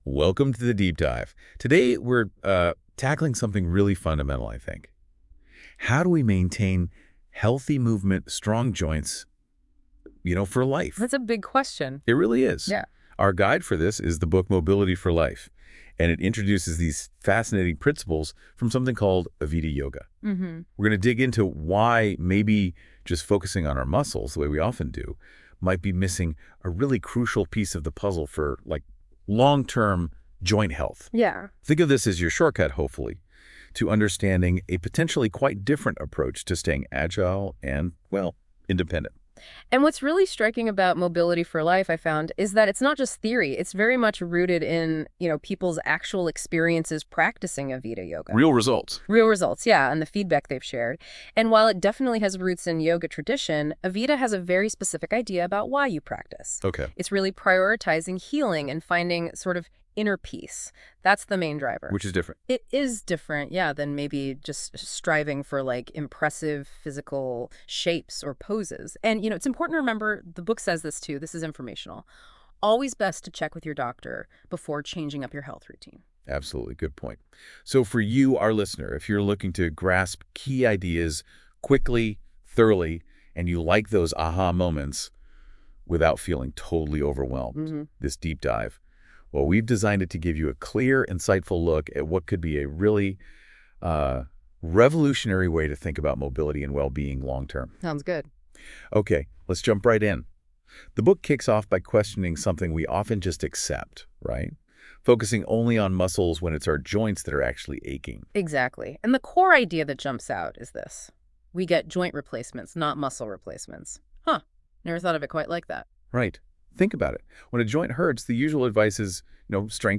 AI conversation on The Avita Way to Healthy Joints, Strong Bones, and A Peaceful Mind.